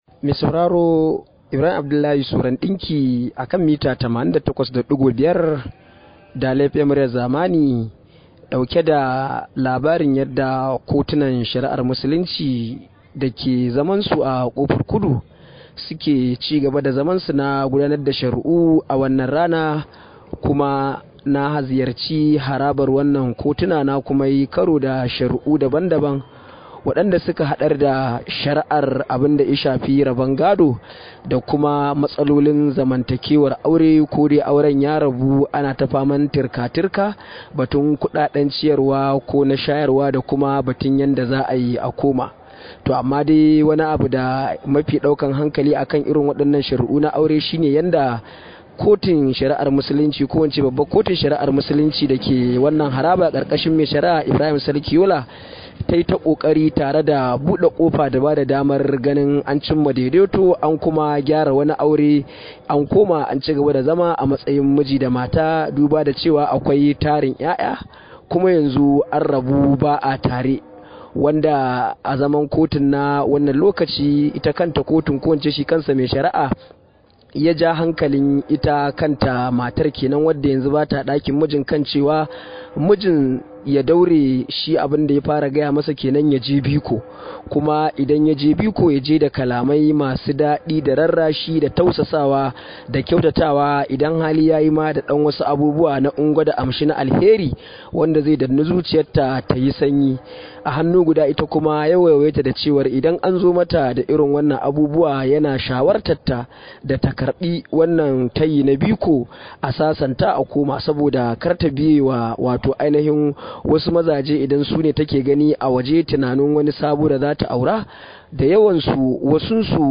Rahoto: Yadda kotu ta yi kokarin sasanta ma’aurata